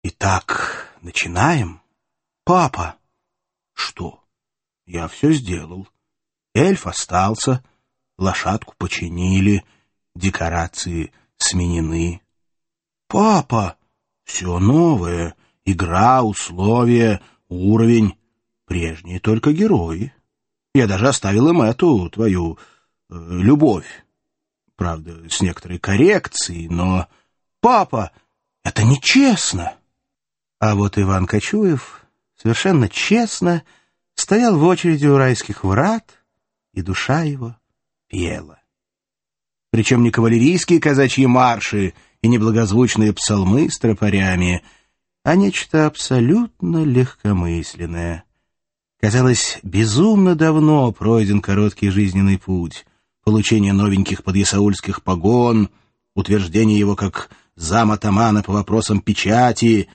Аудиокнига Казак в Аду | Библиотека аудиокниг
Прослушать и бесплатно скачать фрагмент аудиокниги